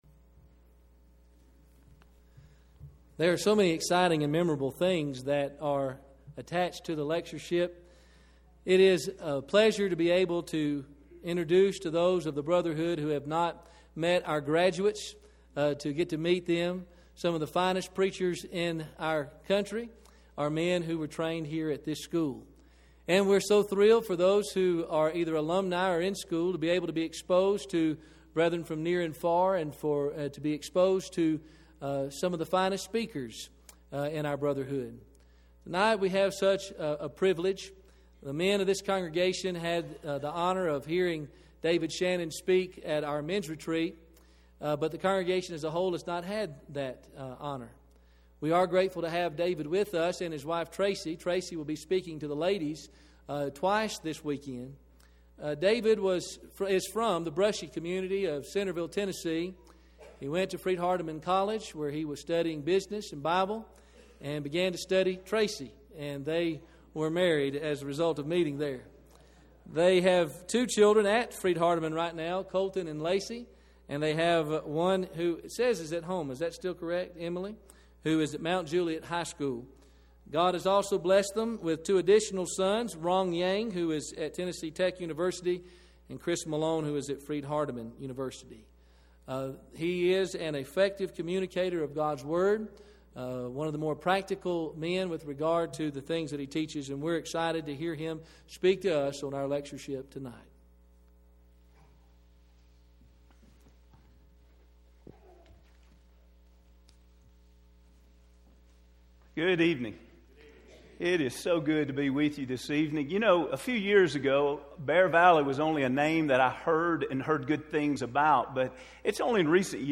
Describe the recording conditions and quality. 2011 Bear Valley Lectures - Who Is This Jesus? A Detailed Study of the Gospel of Mark